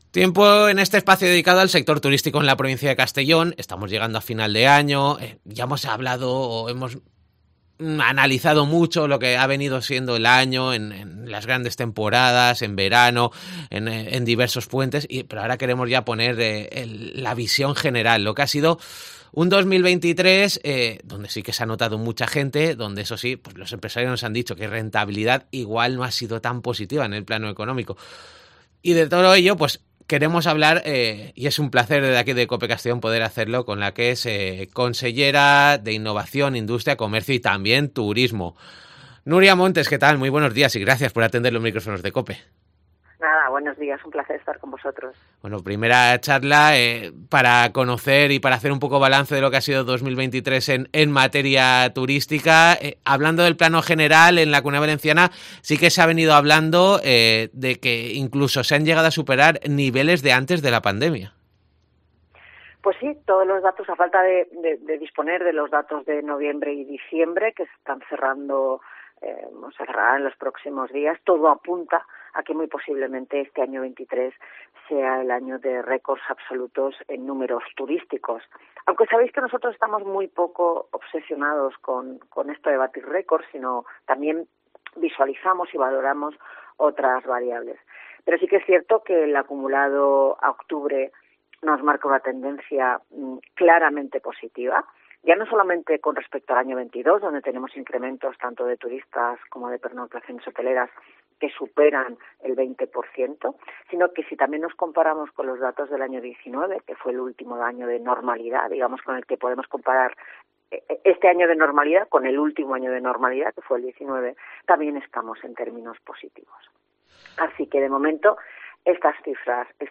Así nos lo ha analizado en COPE Castellón la consellera de Turismo, Nuria Montes , en una entrevista donde para iniciar ha apuntado que " todo apunta a que este año sea de récords absolutos en datos turísticos.